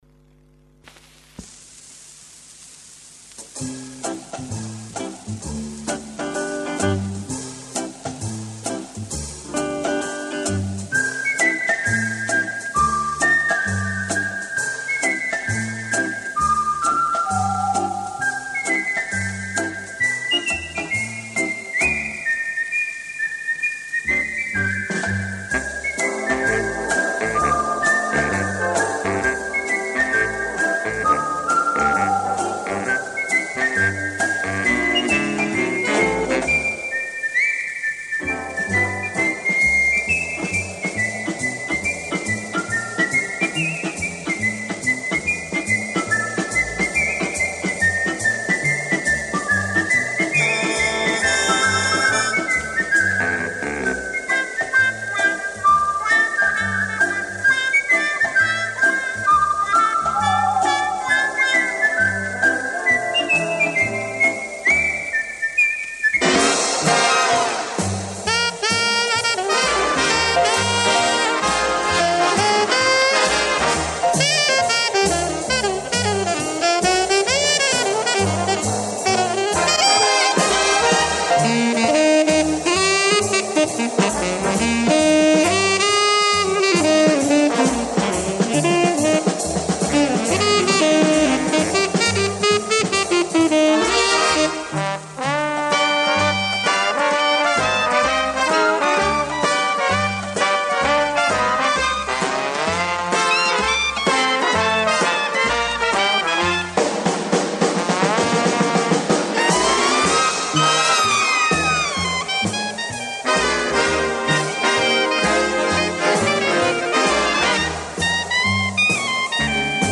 свист